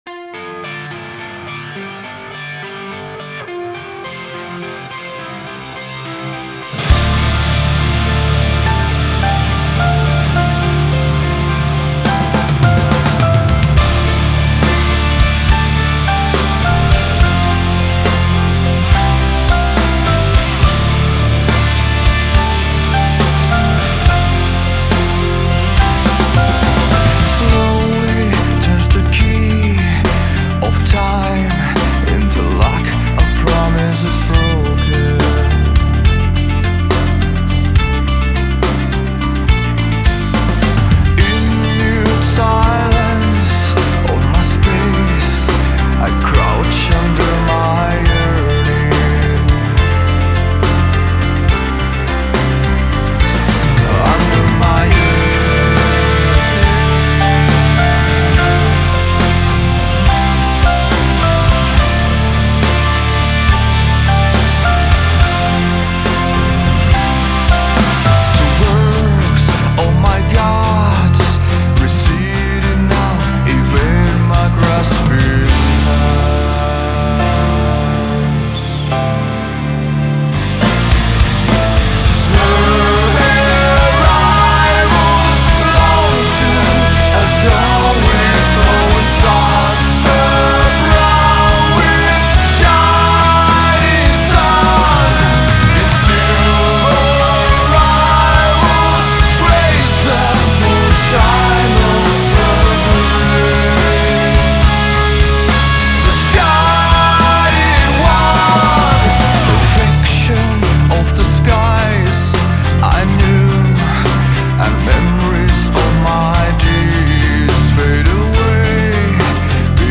progressive metal Folk Metal